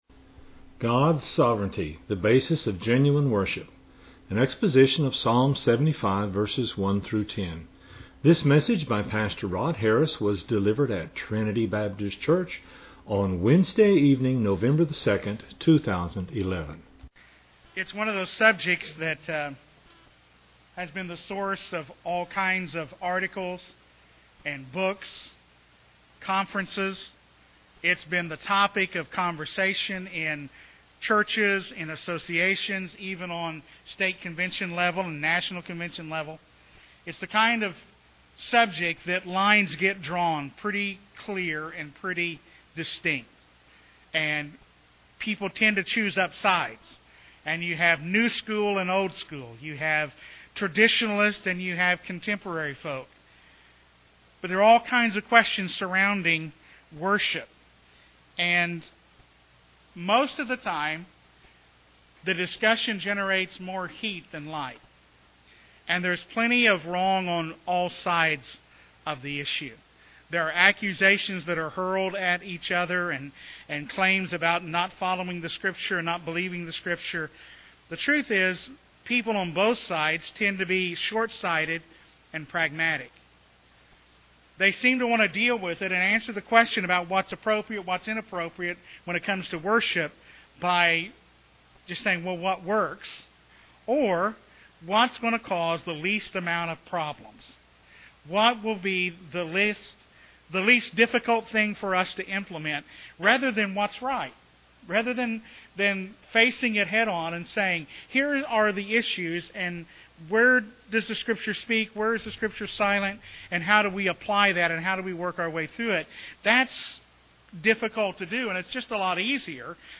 An exposition of Psalm 75:1-10.